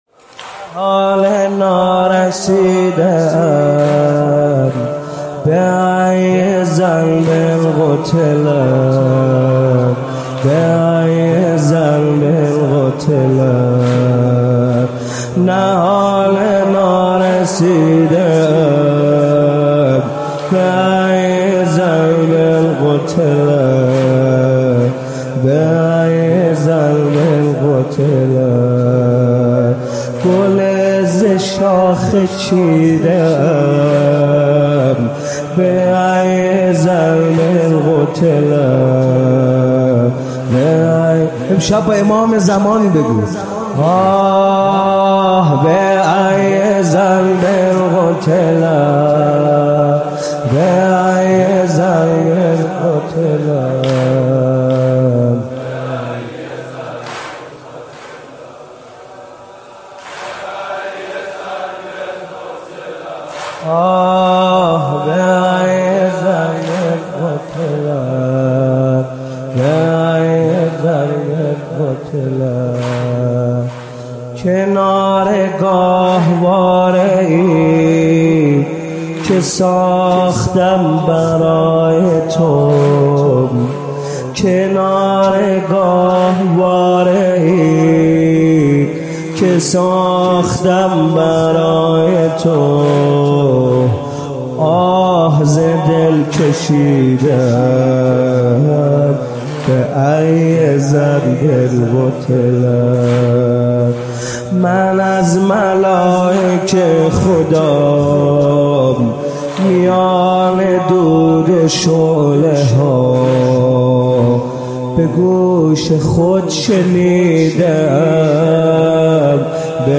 مداحی ایام فاطمیه